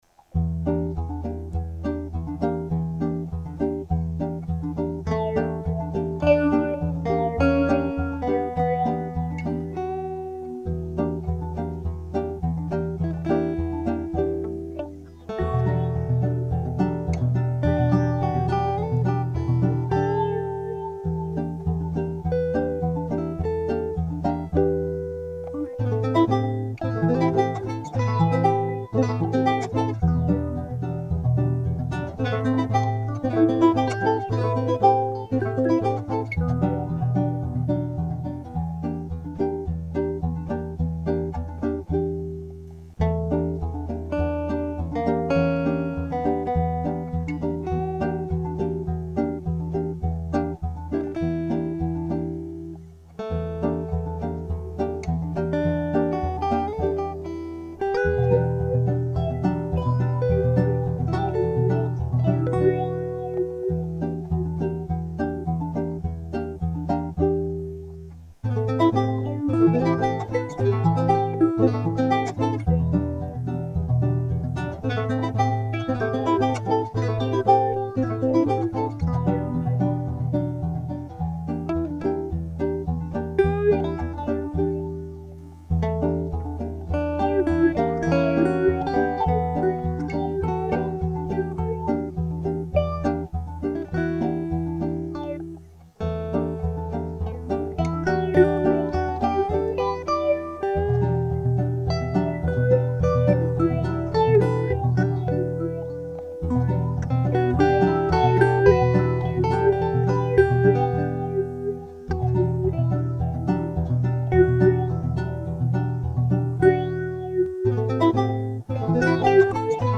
all instruments